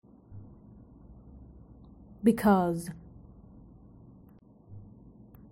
Listen to the pronunciation of 'because'.